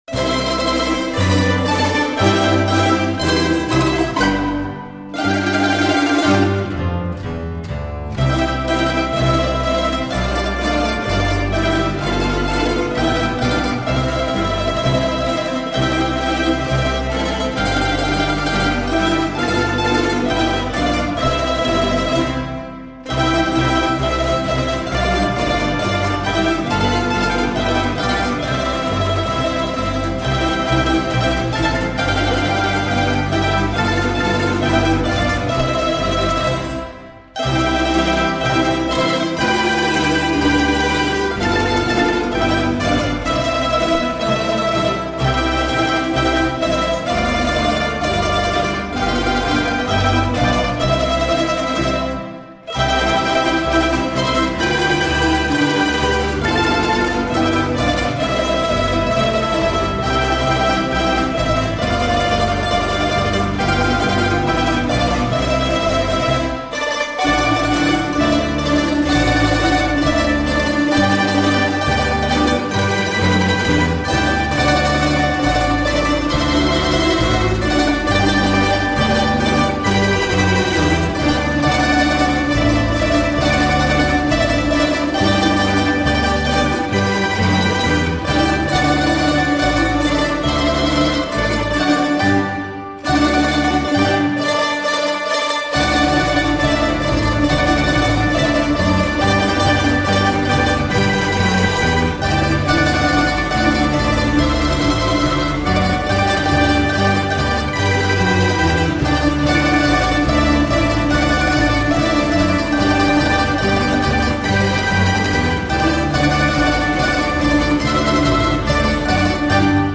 venček slo. nar. prir.